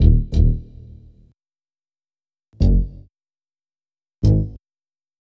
HP092BASS1-R.wav